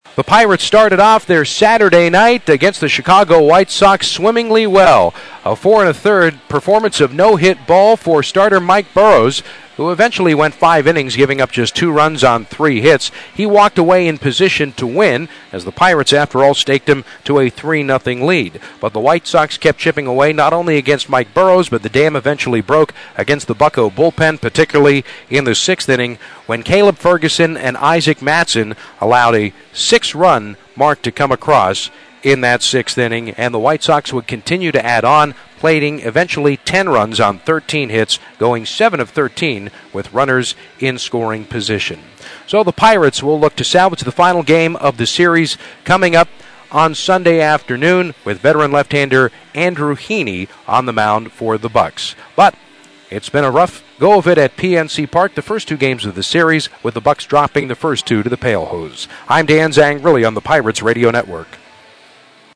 7-19-recap-1.mp3